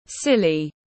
Silly /’sili/